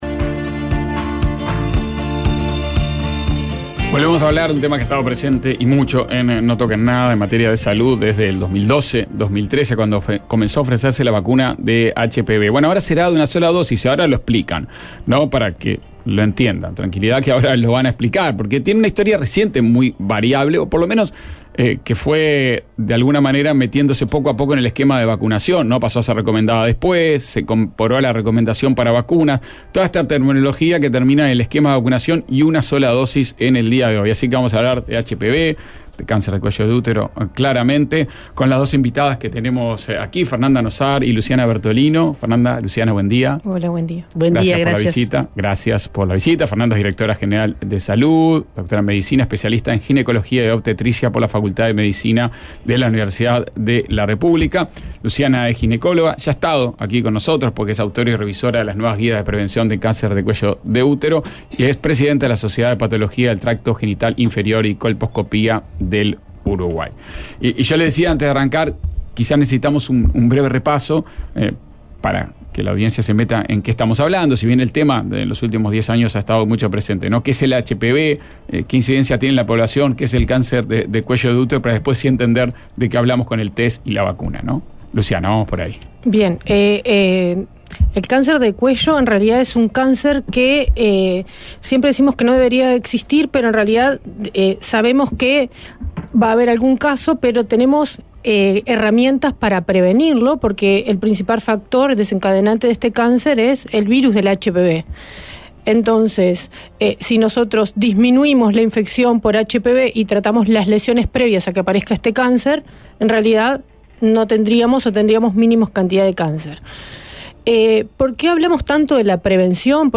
Una mirada desde la cultura a los partidos políticos y las ideas. Entrevista